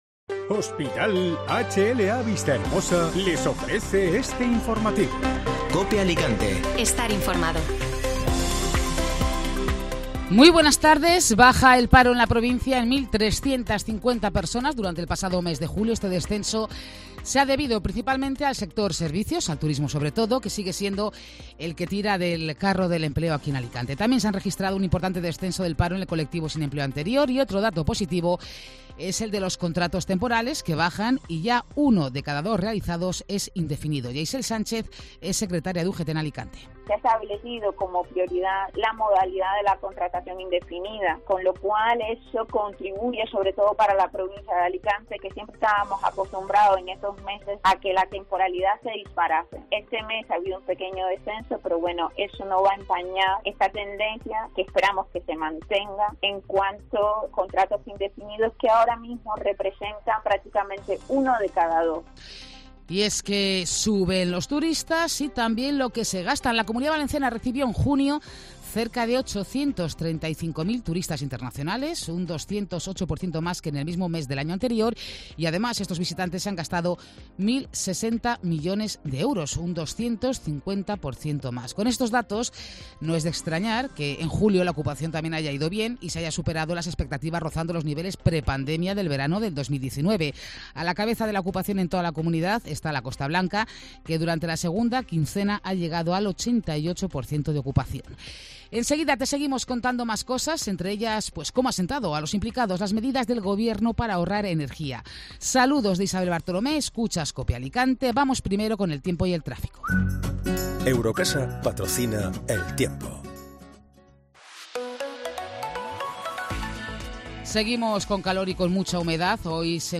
Informativo Mediodía Cope (Martes 2 de Agosto)